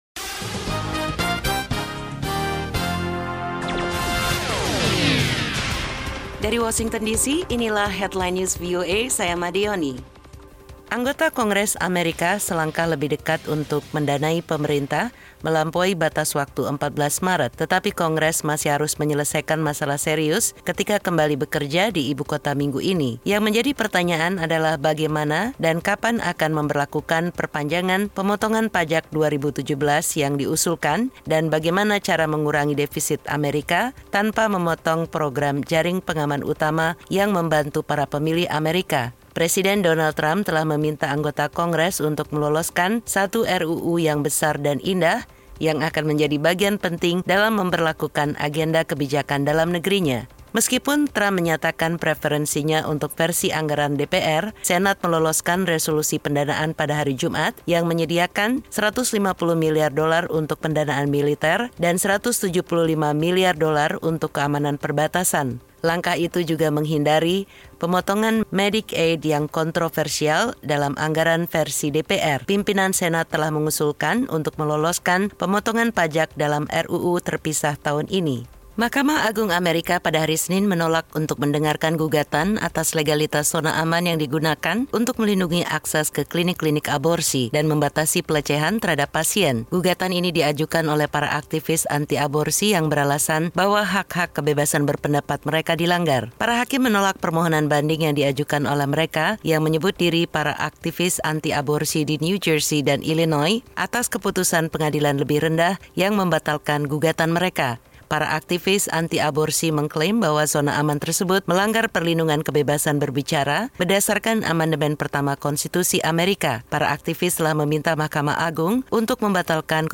Laporan Radio VOA Indonesia